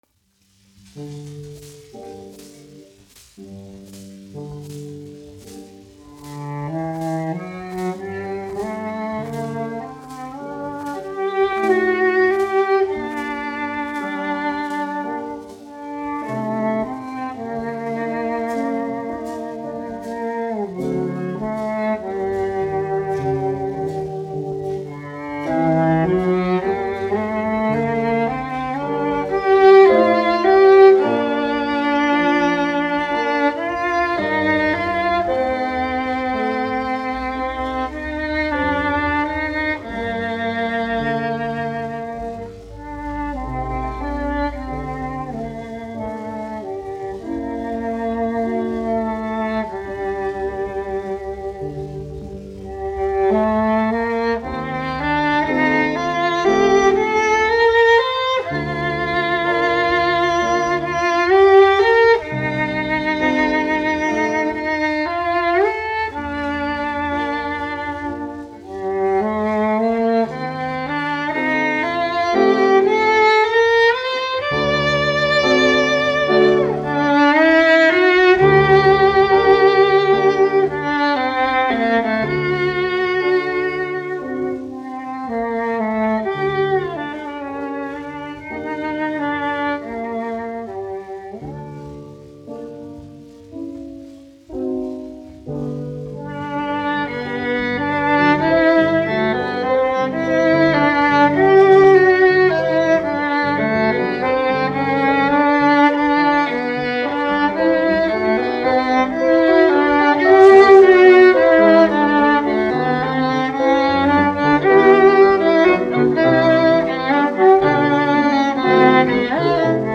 1 skpl. : analogs, 78 apgr/min, mono ; 25 cm
Čella un klavieru mūzika, aranžējumi
Skaņuplate
Latvijas vēsturiskie šellaka skaņuplašu ieraksti (Kolekcija)